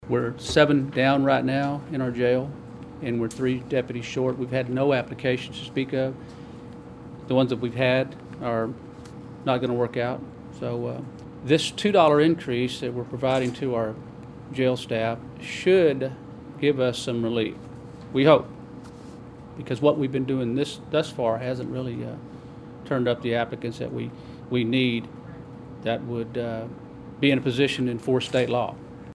Washington County Sheriff Otto Hanak tells county commissioners on Tuesday that the hourly wage increases for employees at the sheriff's office and jail should help with recruiting and retaining staff.